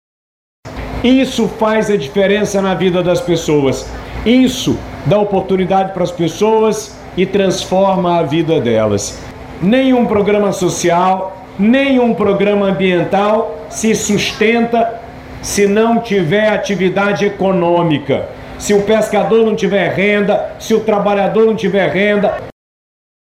A medida visa modernizar o transporte do pescado e ampliar acesso ao financiamento para pescadores, como destaca o governador Wilson Lima.